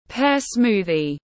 Sinh tố lê tiếng anh gọi là pear smoothie, phiên âm tiếng anh đọc là /peə ˈsmuː.ði/
Pear smoothie /peə ˈsmuː.ði/